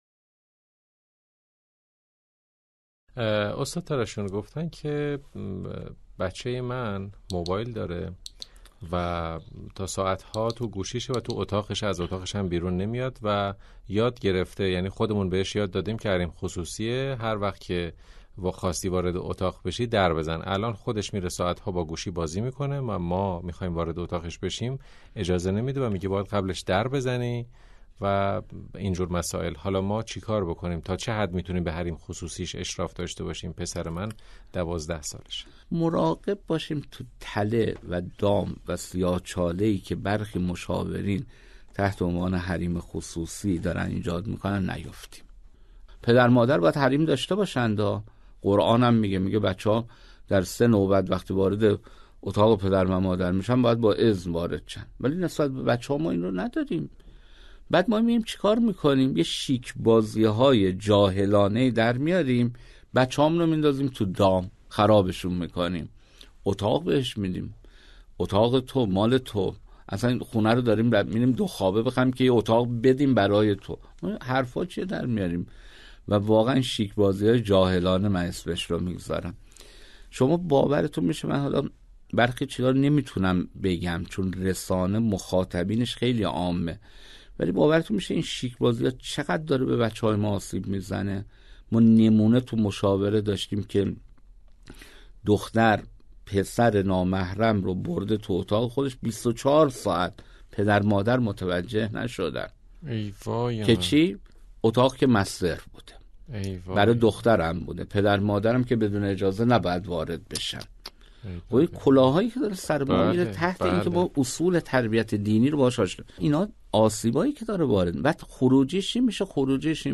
پرسش و پاسخی